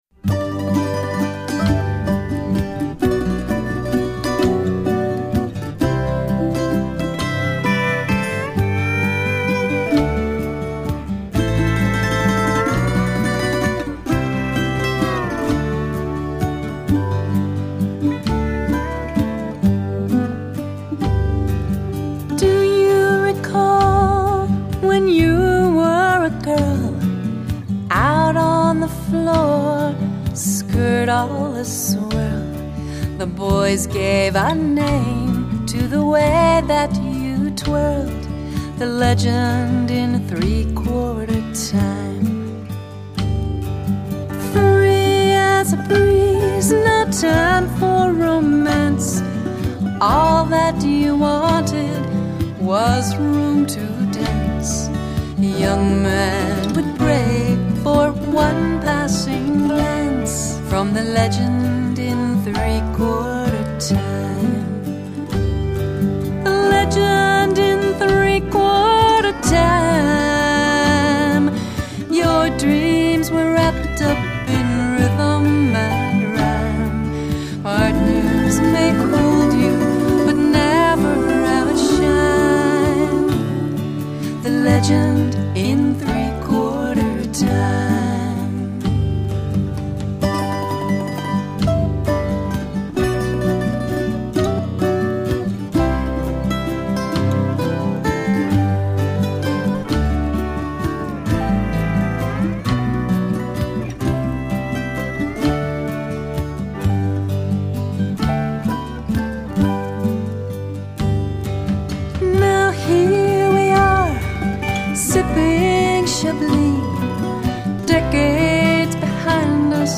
Final CD mix
Drums
Keyboards
MSA pedal steel, bass, mandolin